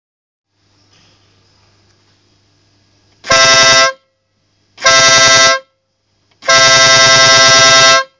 Tromba elettromagnetica Evo - FISA FISA - Speedup
Omologazione CEE-ECE. 115 dB a 2 mt. Potenza assorbita circa 240 Watt. Frequenza ALTA circa 620 Hz. Frequenza BASSA circa 515 Hz Fisa suono diesis.mp3
Fisa_Suono_DIESIS.mp3